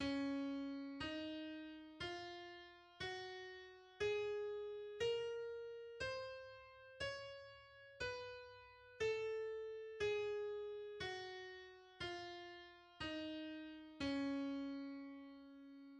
The C-sharp major scale is:
The C-sharp harmonic major and melodic major scales are: